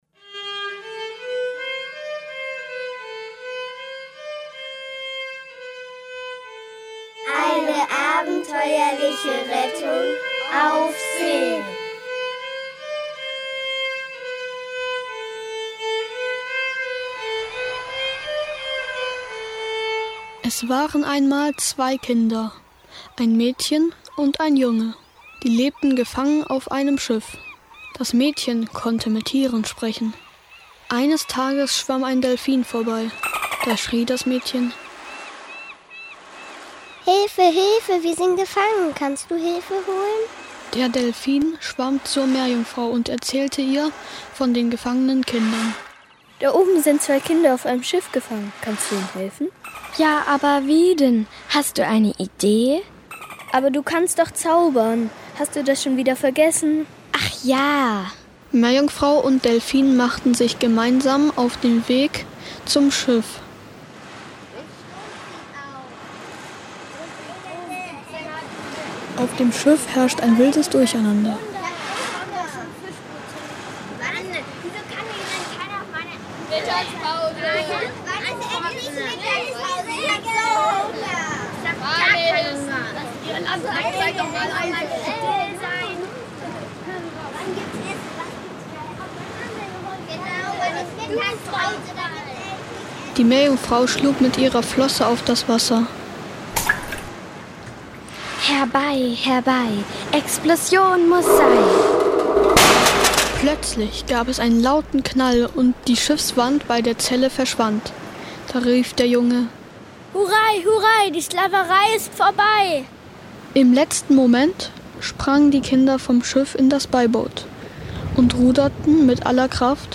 Kaum zu glauben! In nur drei Tagen ist ein spannendes Kurzhörspiel während des Ferienworkshops "Kinder machen ein Hörspiel" entstanden.
Nachdem sich am ersten Tag alle zusammen eine Geschichte ausgedacht hatten, ging es am zweiten Tag auf die Suche nach passenden Geräuschen.
Schnell waren die Rollen verteilt und alle übten ihren Text, um ihn dann in die Mikros einzusprechen.
Am Schluss blieb sogar noch Zeit, live improvisierte Musik für den Anfang und das Ende des Hörspiels aufzunehmen.